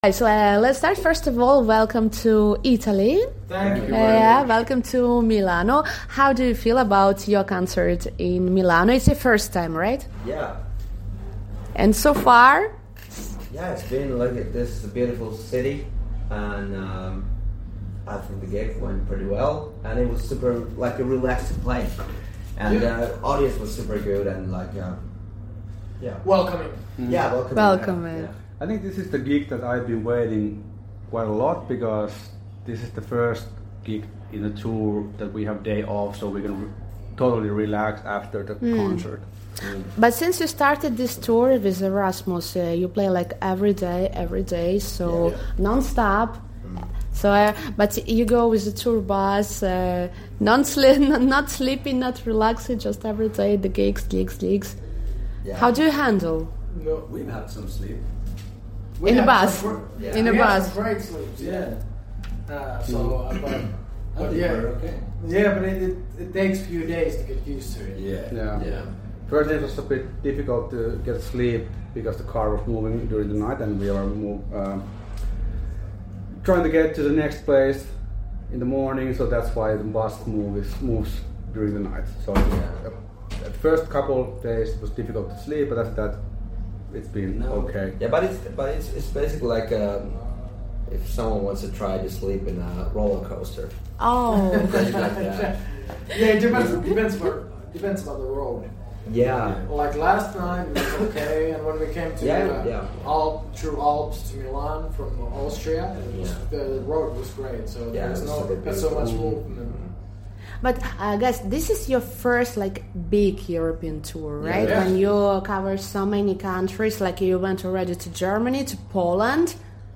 Block-of-flats-interview.-Milano.mp3